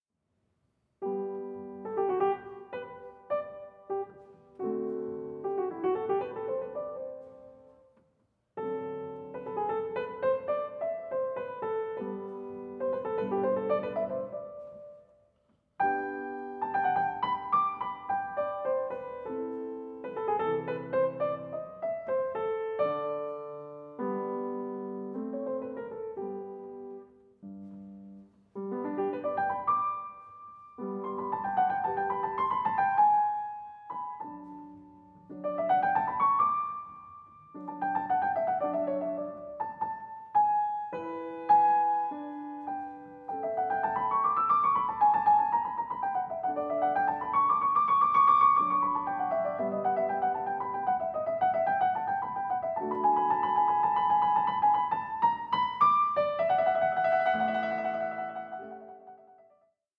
Piano Sonata in E Minor